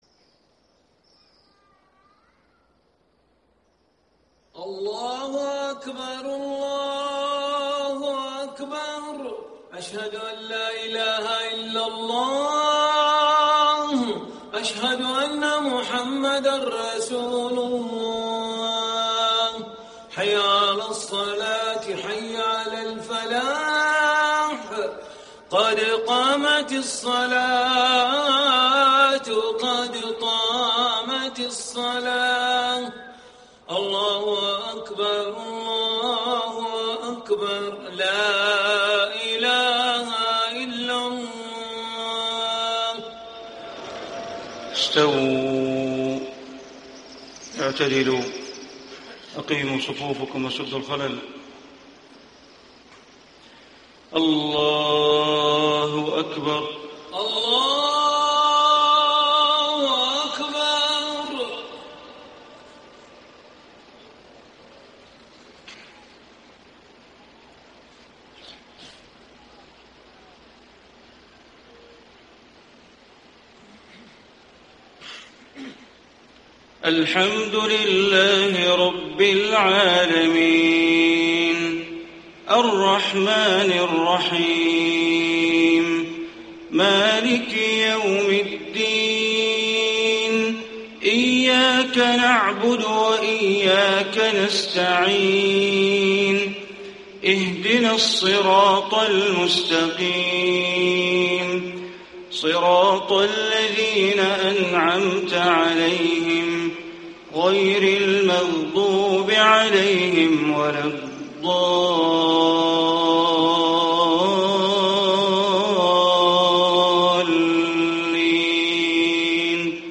صلاة الفجر 3-2-1435 سورتي السجدة و الإنسان > 1435 🕋 > الفروض - تلاوات الحرمين